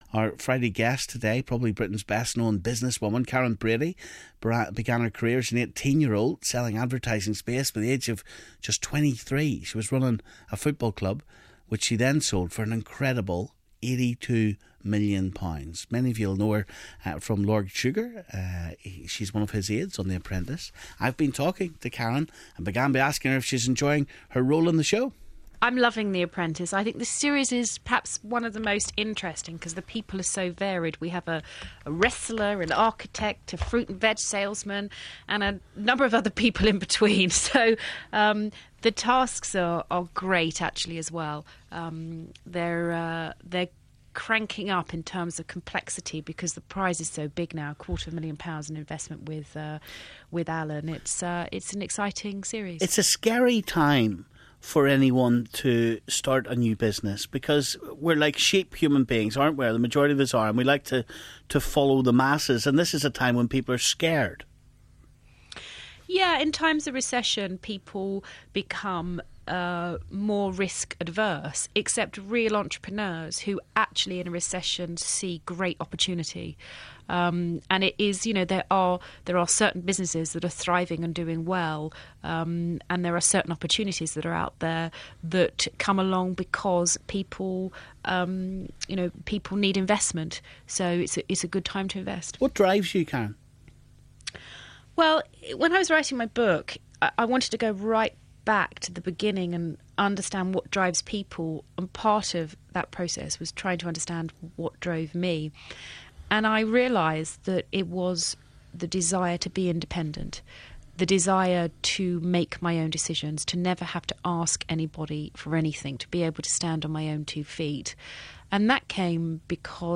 Our Big Friday Interview - Karren Brady talks about The Apprentice, women in football and when she was diagnosed with a brain aneurism